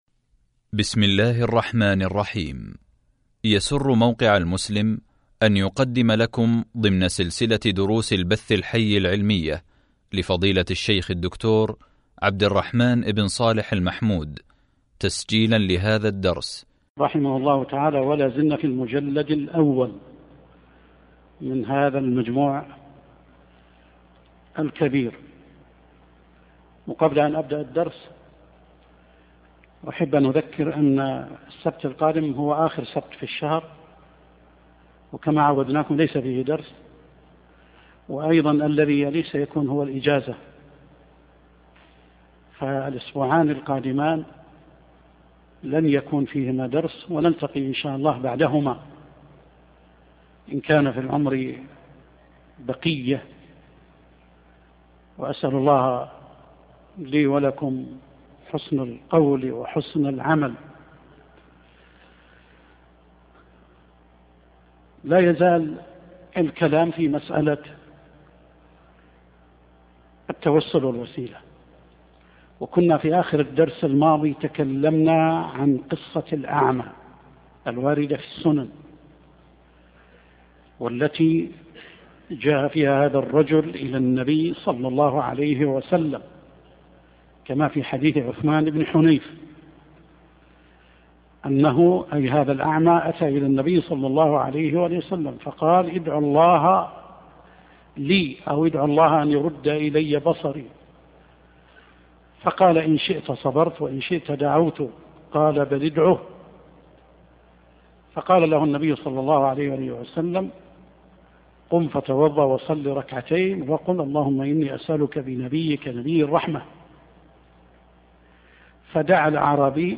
فتاوى ابن تيمية - الدرس الثاني عشر | موقع المسلم